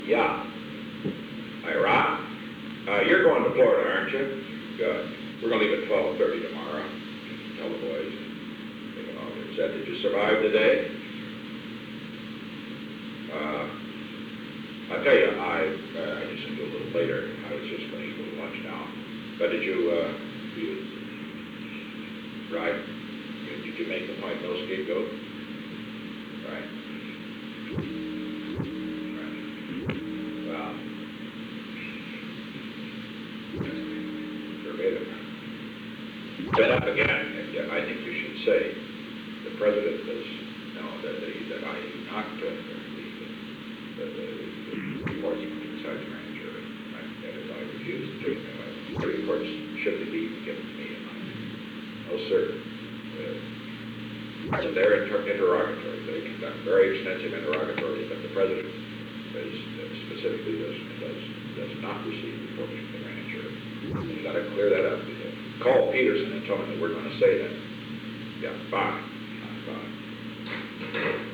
Secret White House Tapes
Conversation No. 429-4
Location: Executive Office Building
The President talked with Ronald L. Ziegler.